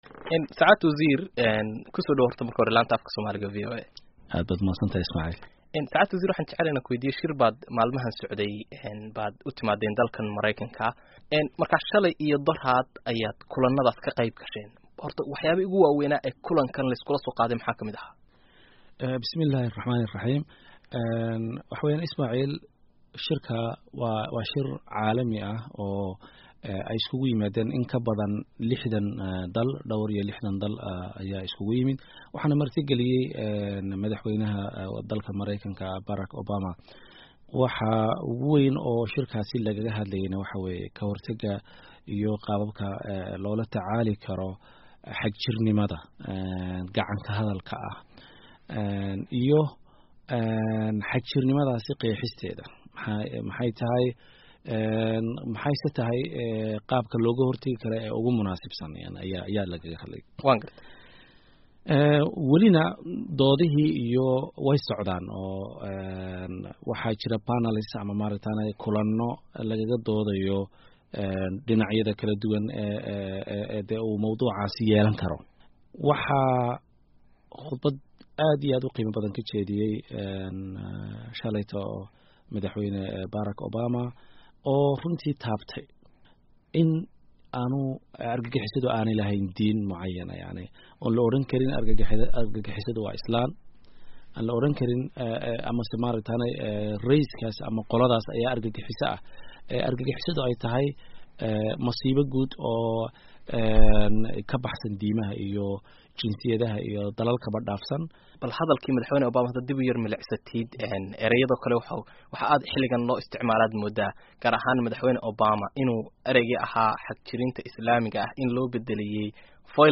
Qaybta Hore ee Waraysiga Wasiir Aadan